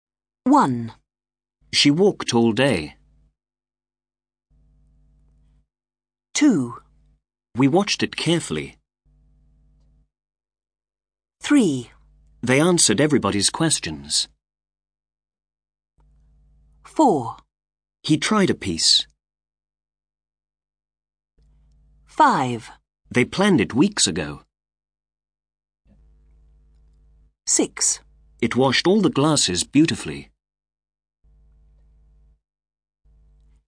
When the verb is linked to the next word because that word starts with a vowel sound , the endings are much clearer
ACTIVITY 3. Listen to these sentences and pay attention to the links.